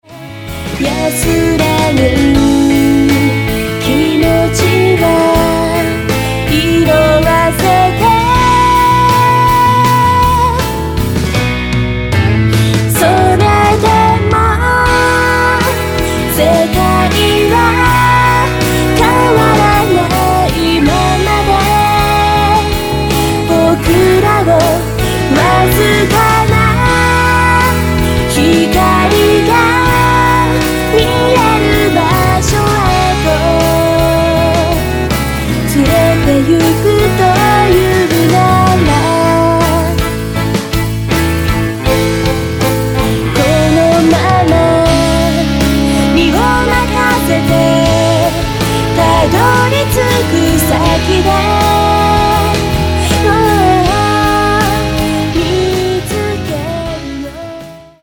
Electric & Acoustic Guitars and Basses :